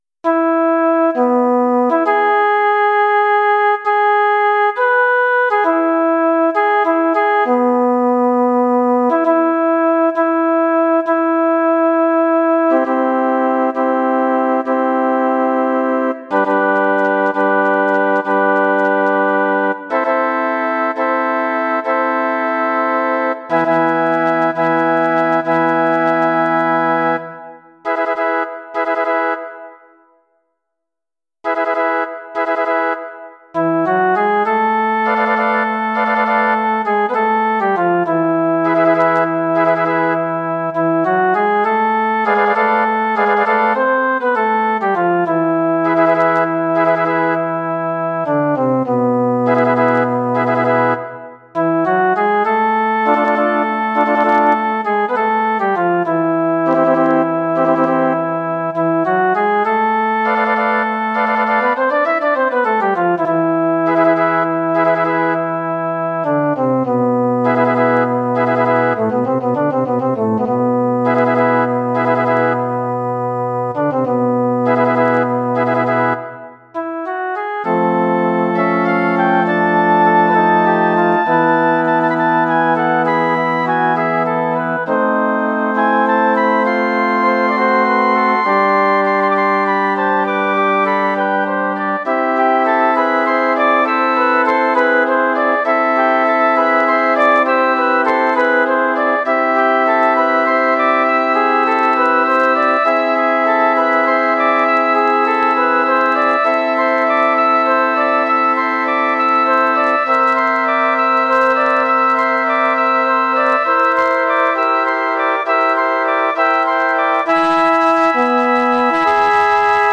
Bearbeitung für Flötenensemble
Arrangement for flute ensemble